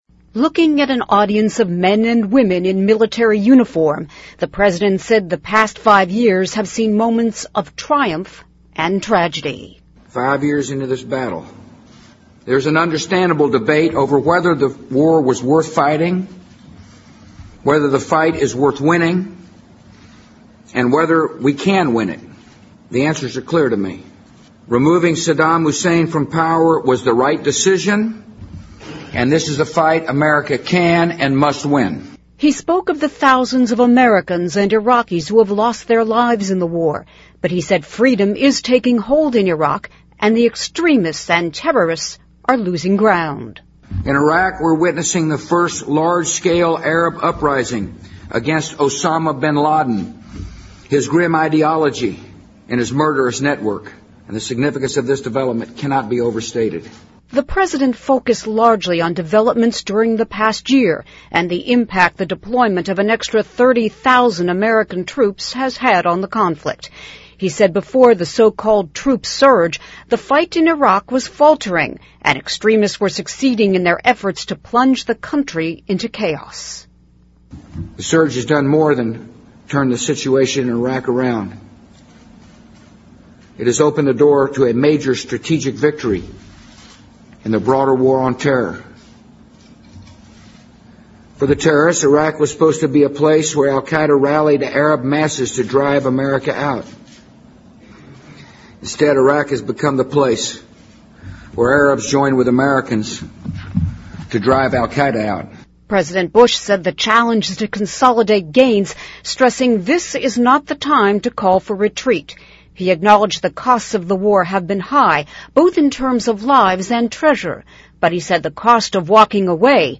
News
President Bush marked the fifth anniversary of the start of the war in Iraq with a speech at the U.S. Defense Department.
Looking at an audience of men and women in military uniform, the president said the past five years have seen moments of triumph and tragedy.